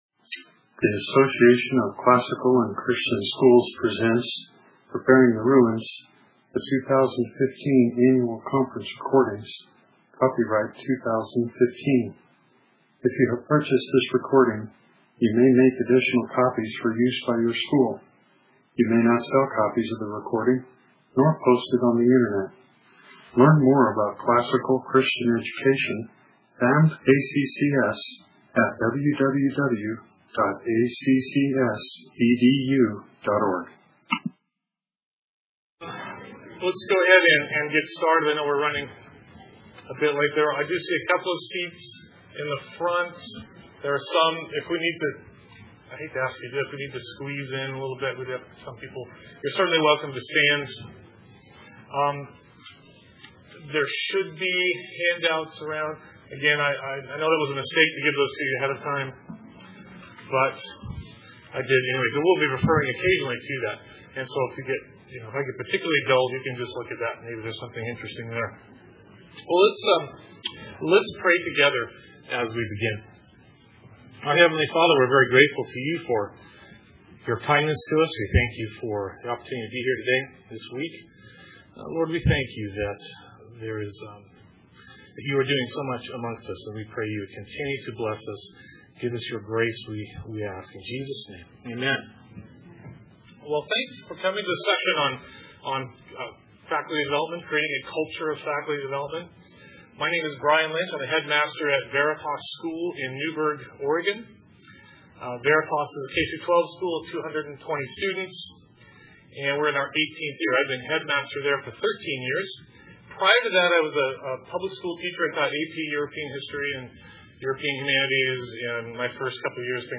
2015 Leaders Day Talk | 0:53:20 | All Grade Levels, Leadership & Strategic, Marketing & Growth, Teacher & Classroom
Additional Materials The Association of Classical & Christian Schools presents Repairing the Ruins, the ACCS annual conference, copyright ACCS.